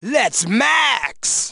Root > content > SFX & Announcers > DDR Extreme SFX